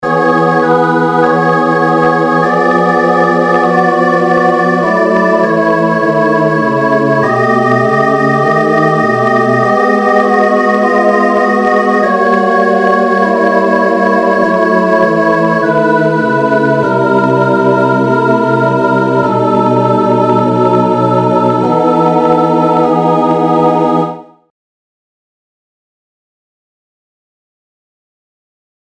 corrige realisation a 4 voix 21.mp3